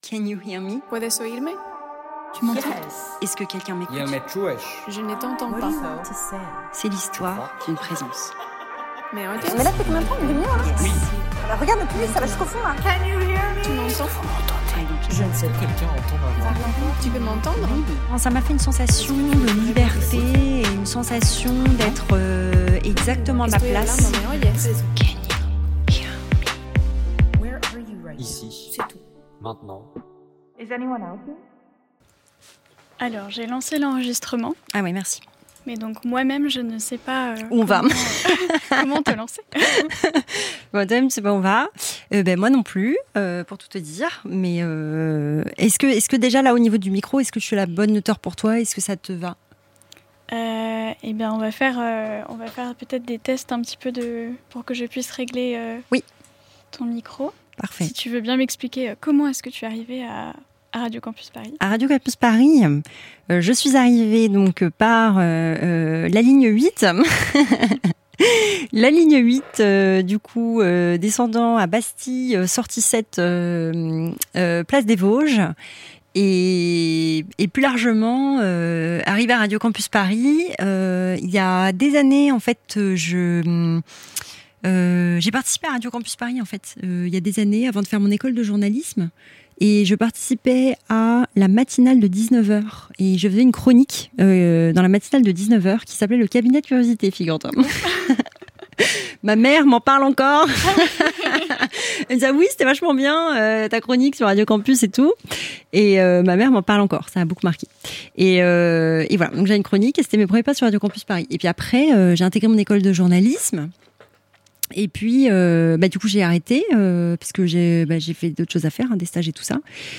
Création sonore